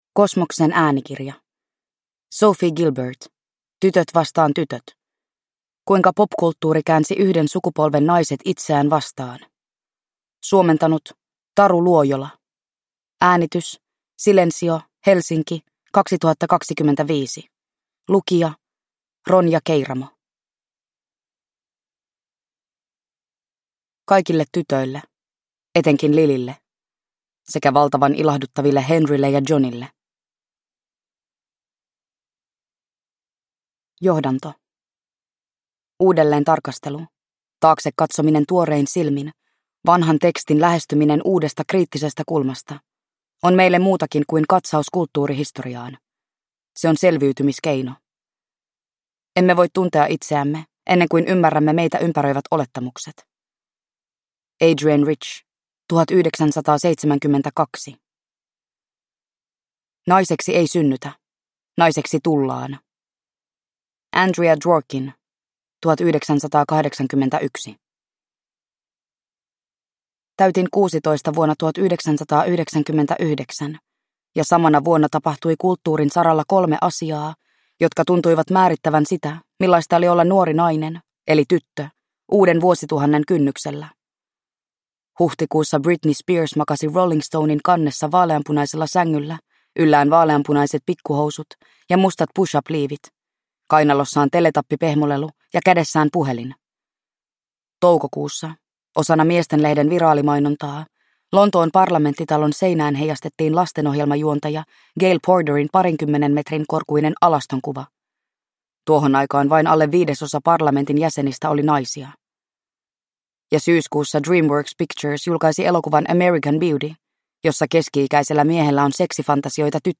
Tytöt vs. tytöt – Ljudbok